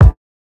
Kicks
take care kick ~.wav